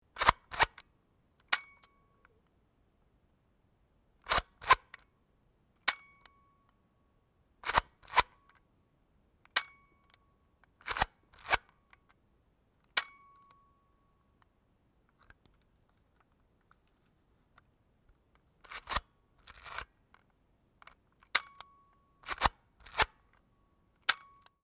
Agfamatic 5008 Makro Pocket camera